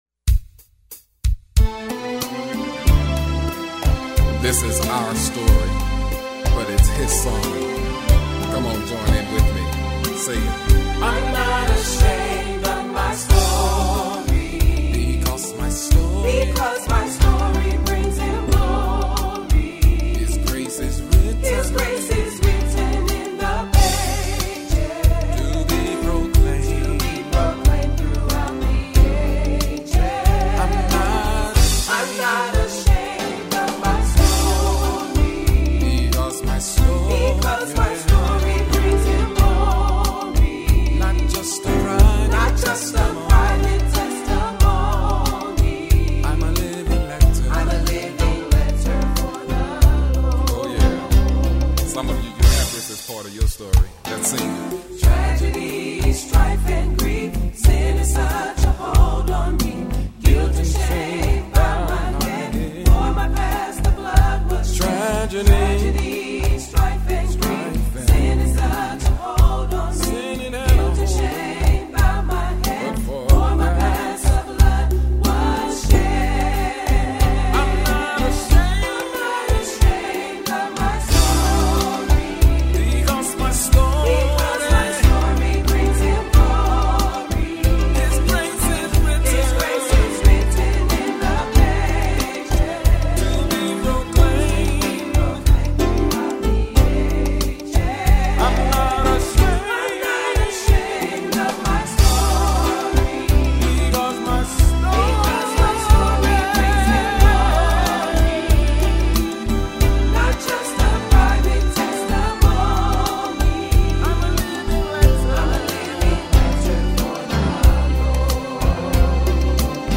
uniquely, simplistic style